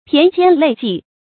駢肩累跡 注音： ㄆㄧㄢˊ ㄐㄧㄢ ㄌㄟˇ ㄐㄧˋ 讀音讀法： 意思解釋： 同「駢肩累跡」。